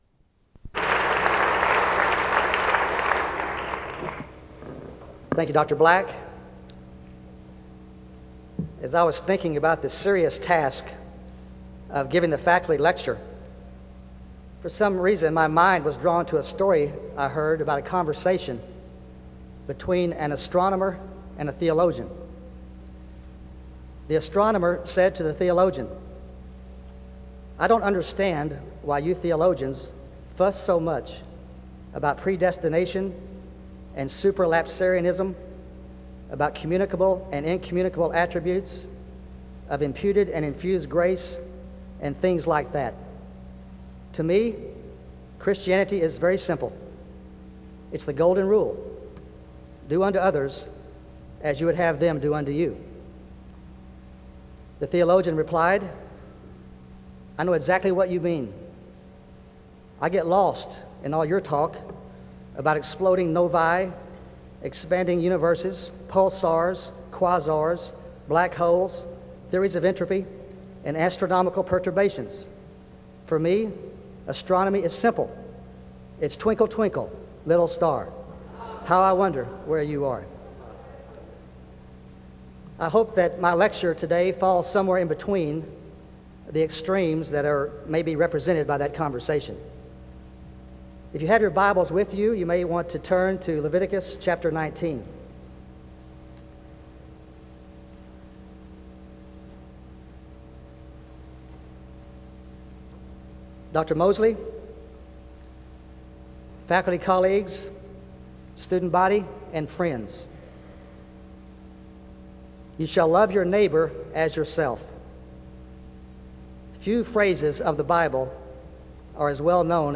SEBTS Chapel
SEBTS Chapel and Special Event Recordings - 2000s